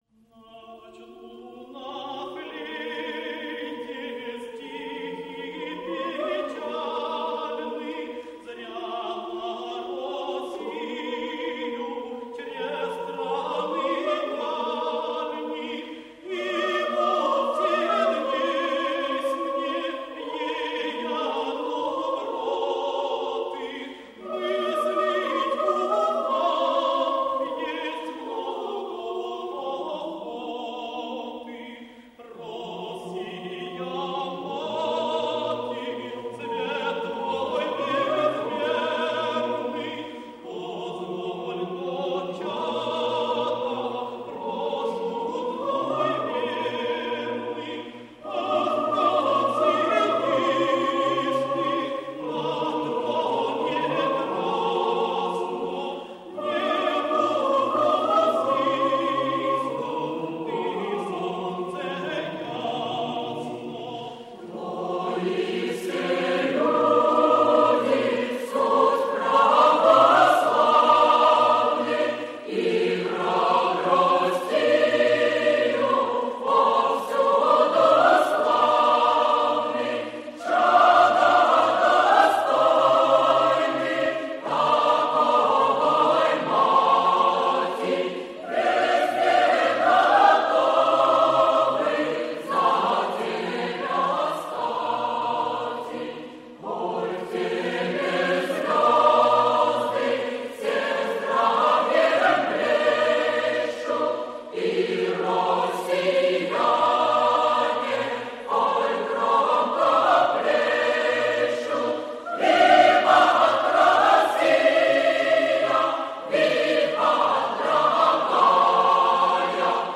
Ретро: Хор ЛЭТИ – Кант Стихи похвальные России муз. народная в обр.